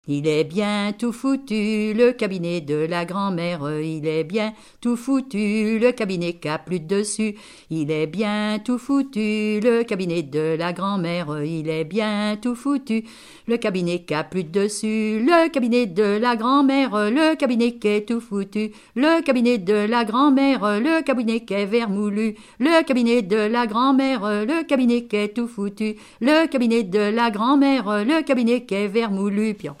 Couplets à danser
danse : brisquet
Pièce musicale inédite